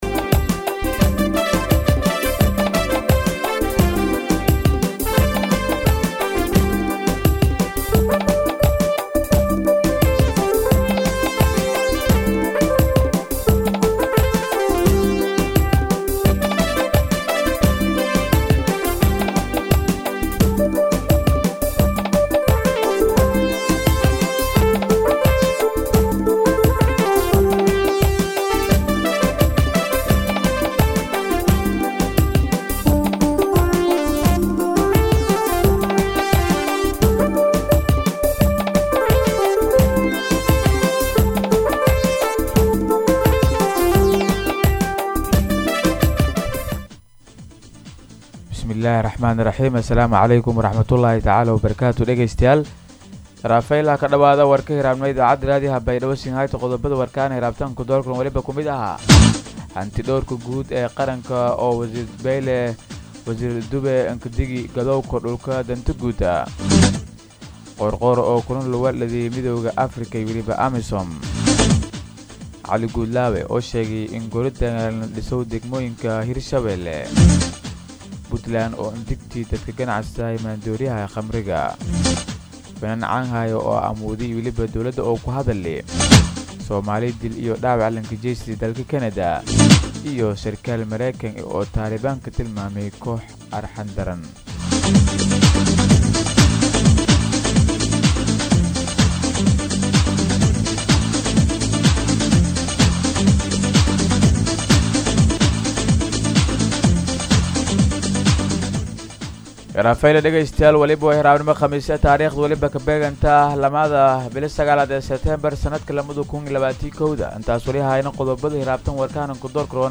DHAGEYSO:-Warka Subaxnimo Radio Baidoa 2-9-2021